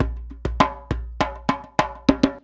100DJEMB17.wav